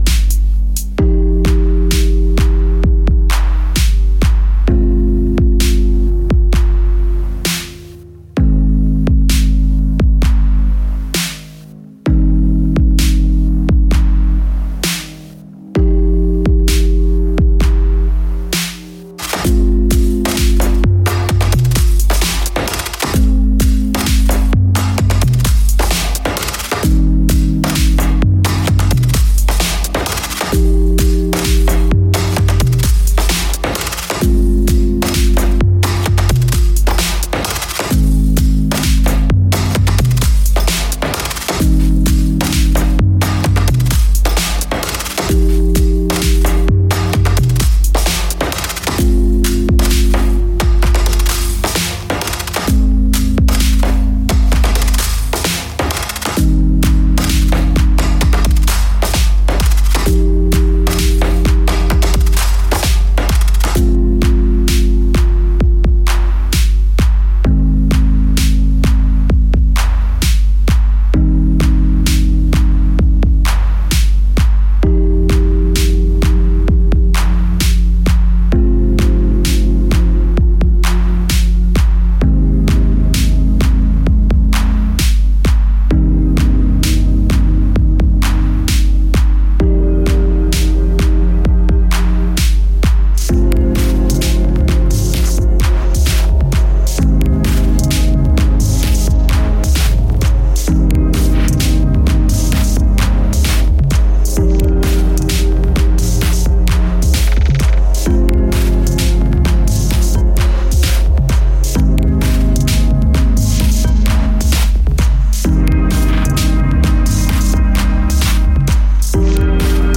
Freestyle take on outside techno and electro